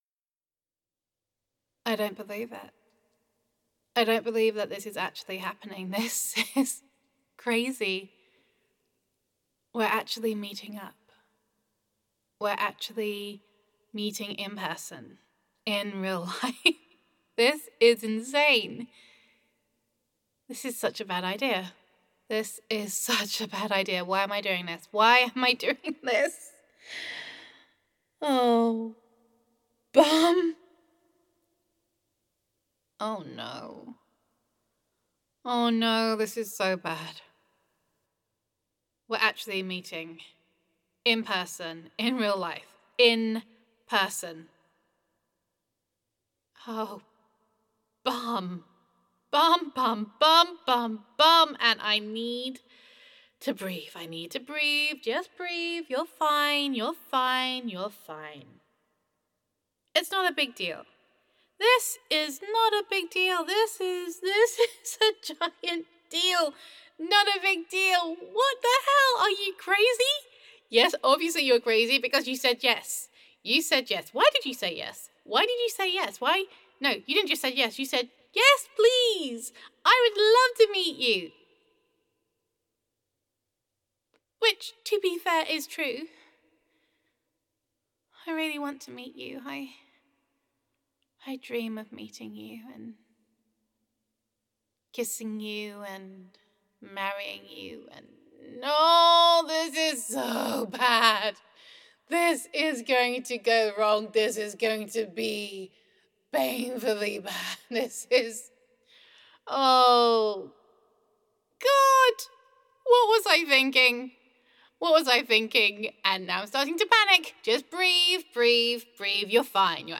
[F4A] Don’t Panic [Inner Monologue][Panic][Worry][Fretting Honey][Online Love][Long Distance Relationship][Meeting for the First Time][Gender Neutral][Waiting to Meet Your Online Love for the First Time]